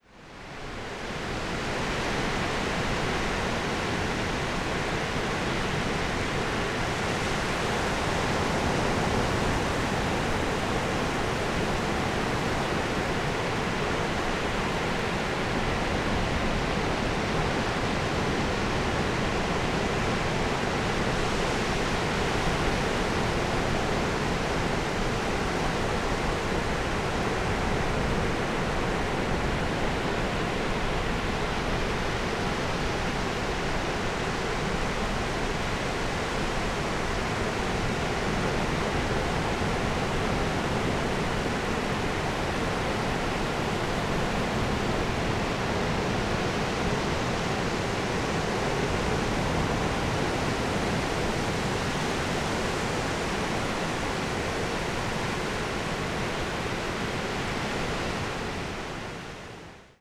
- parce que c’est très difficile d’enregistrer (et de restituer) le bruit de l’océan capté d’un peu loin, 50 ou 100 mètres, j’étais au bord d’une baïne à marée basse. À l’écoute on se dit qu’on aurait pu faire aussi bien, voire plus évocateur avec le bruit blanc du synthétiseur ou de Pure Data ;
Plage de la Bouverie
La Tremblade, 2/08/2024, vers 8h30